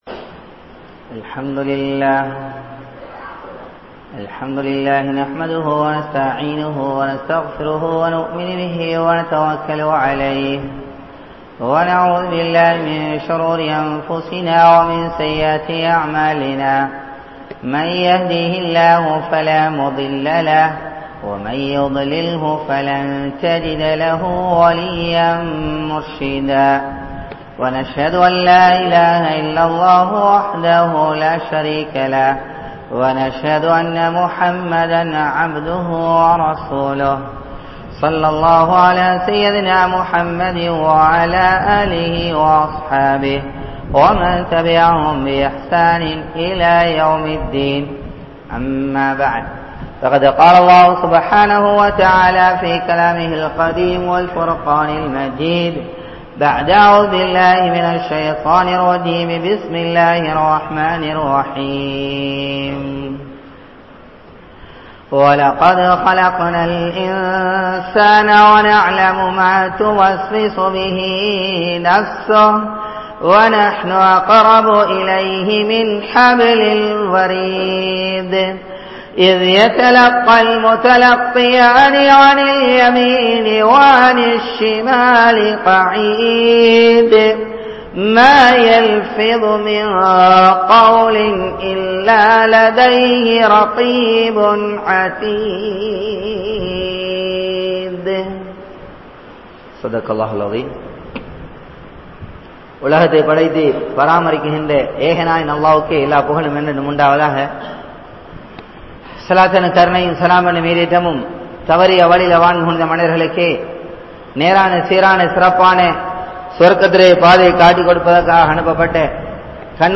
Naavin Vilaivuhal(நாவின் விளைவுகள்) | Audio Bayans | All Ceylon Muslim Youth Community | Addalaichenai
Gorakana Jumuah Masjith